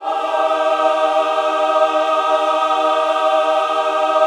Index of /90_sSampleCDs/USB Soundscan vol.28 - Choir Acoustic & Synth [AKAI] 1CD/Partition B/06-MENWO CHD